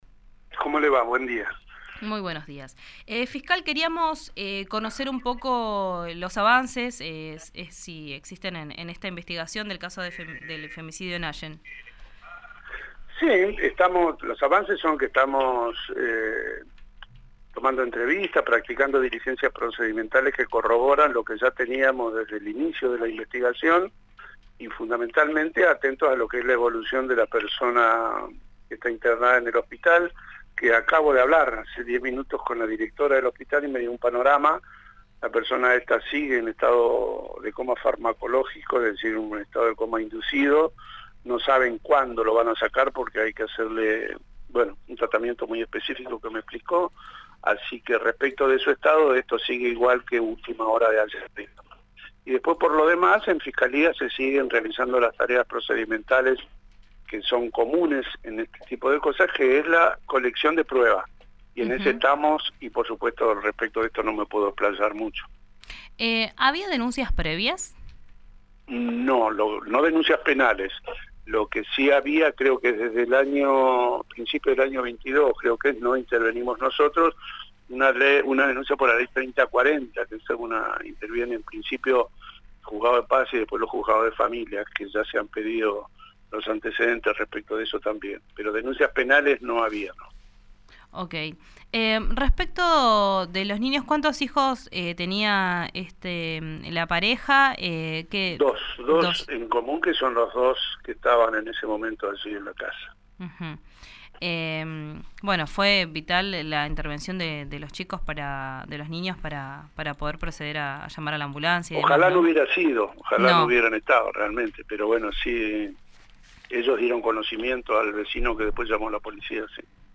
En una entrevista con RIO NEGRO RADIO, el fiscal Romero aseguró que están investigando, recolectando prueba, «tomando entrevistas y practicando diligencias procedimentales que corroboran lo que ya teníamos desde el inicio», planteó.
Escuchá al fiscal Ricardo Romero en RÍO NEGRO RADIO: